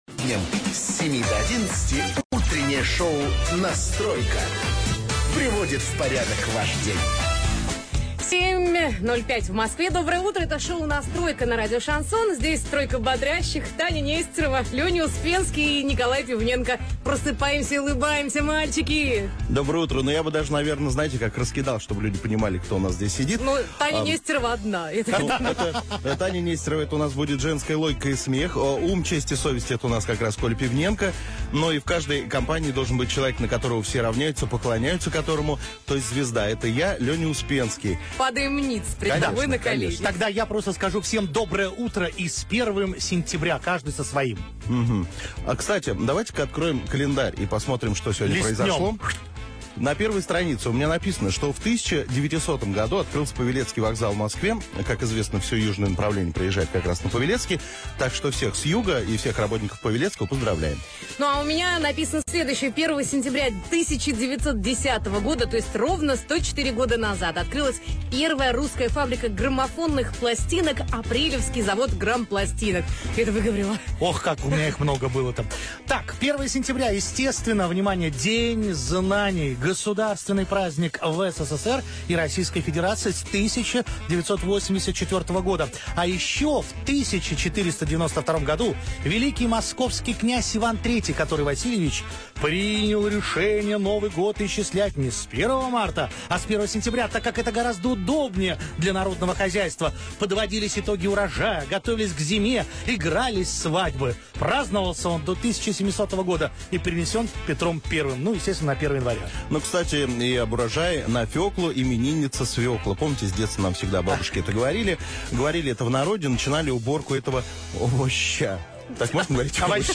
Теперь каждый день по будням трое молодых людей проводят для вас "Настройку".
Формат: Русский шансон